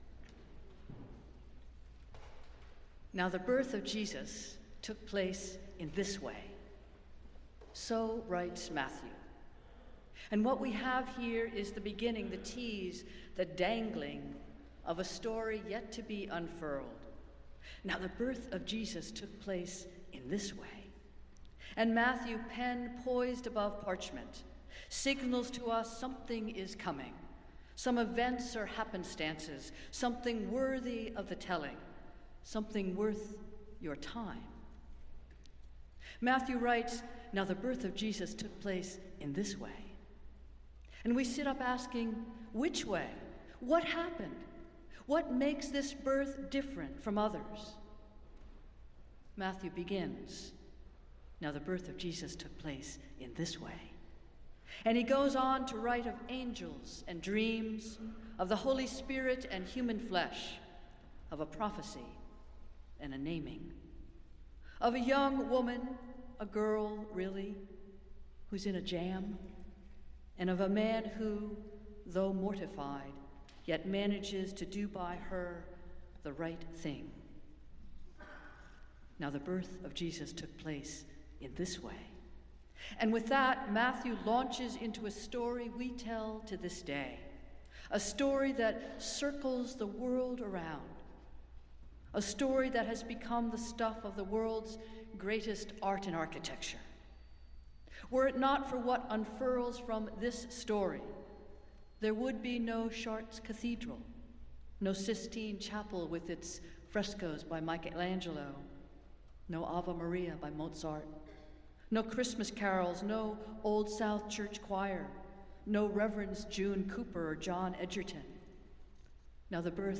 Festival Worship - Third Sunday of Advent